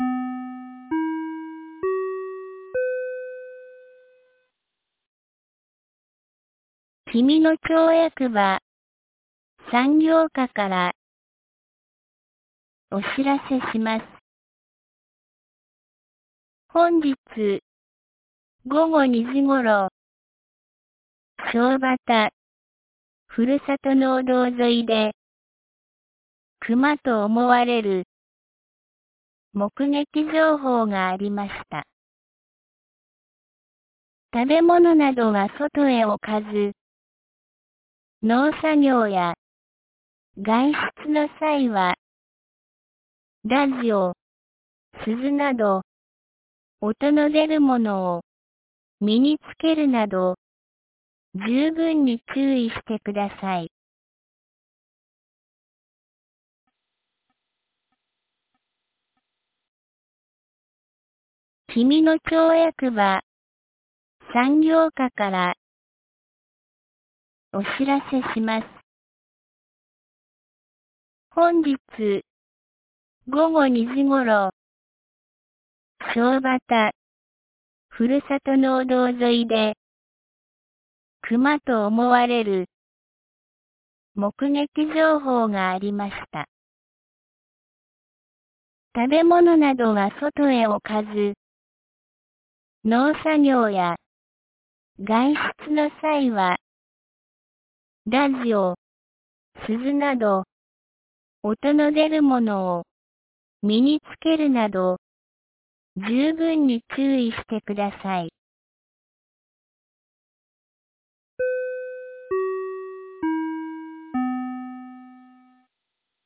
2021年05月15日 17時12分に、紀美野町より東野上地区へ放送がありました。